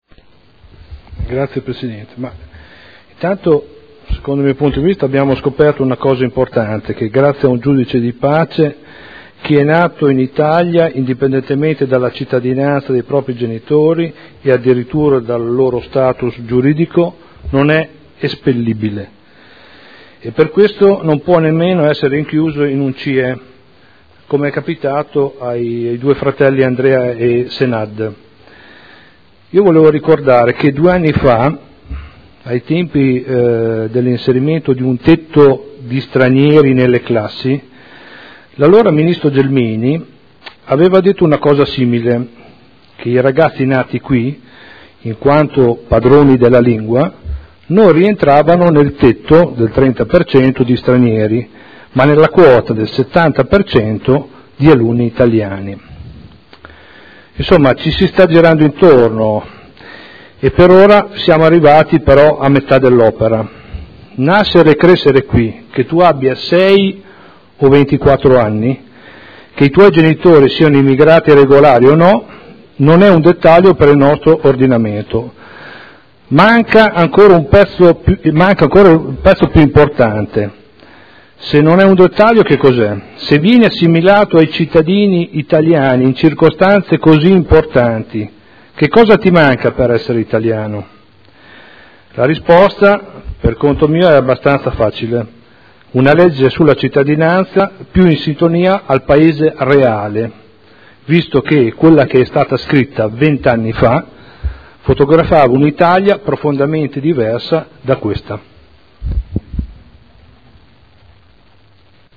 Stefano Prampolini — Sito Audio Consiglio Comunale
Seduta del 03/05/2012.